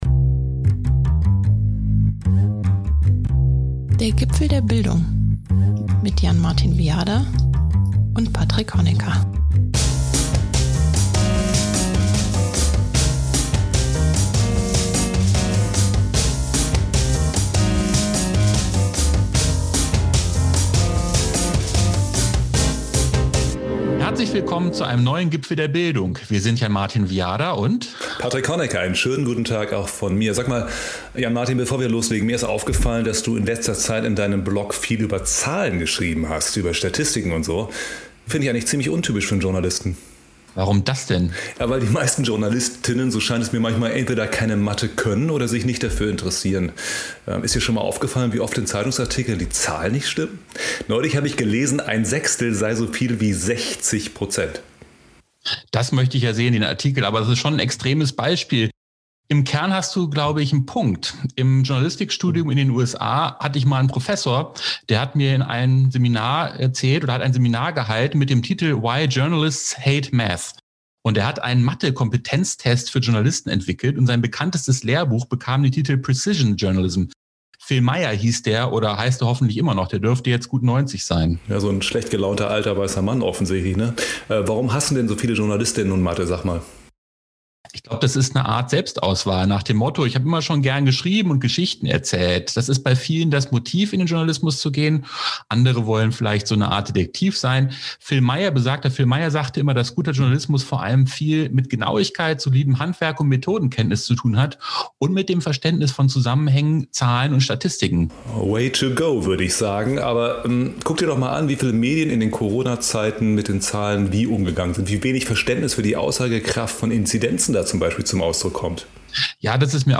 im Gespräch mit Schleswig-Holsteins Bildungsministerin Karin Prien.